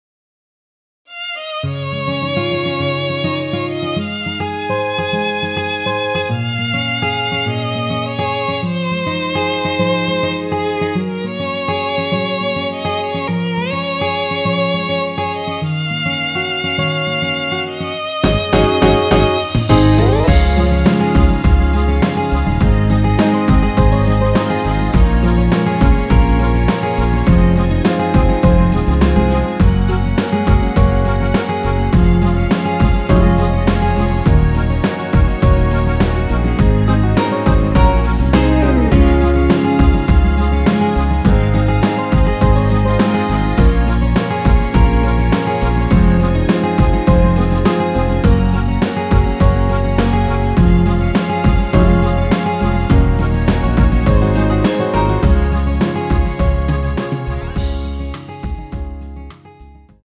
원키에서(-5)내린 MR입니다.
대부분의 남성분이 부르실수 있는키로 제작 하였습니다.(미리듣기 참조)
Db
앞부분30초, 뒷부분30초씩 편집해서 올려 드리고 있습니다.
중간에 음이 끈어지고 다시 나오는 이유는